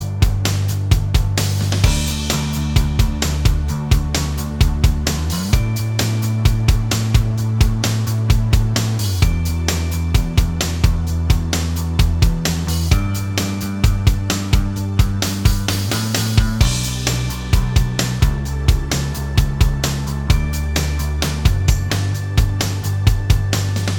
Minus All Guitars Indie / Alternative 4:02 Buy £1.50